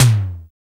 FAT E-TOM.wav